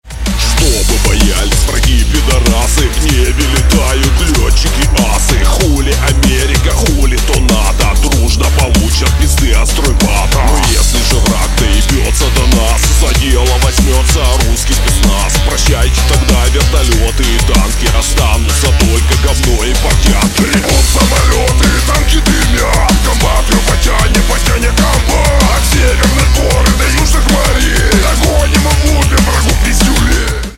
• Качество: 128, Stereo
мужской голос
жесткие
цикличные
с матом